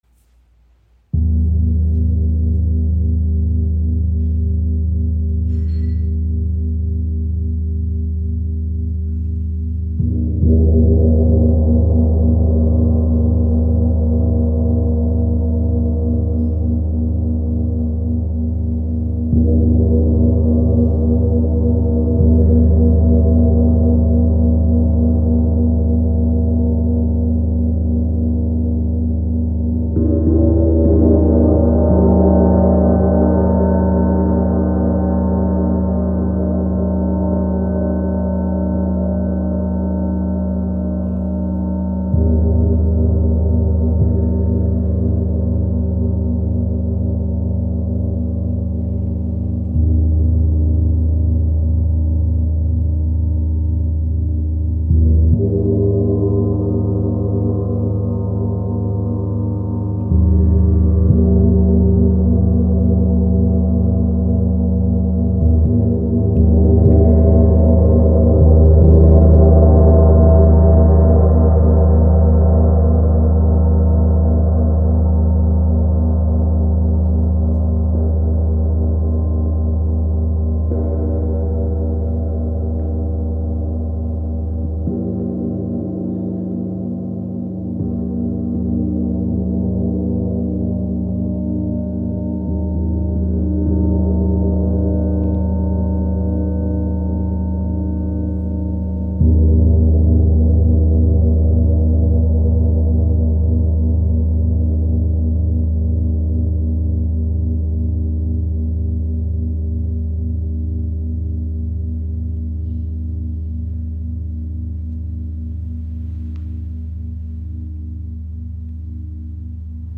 • Icon Bronze und Nickel-Silber erzeugen warme, harmonische Klänge.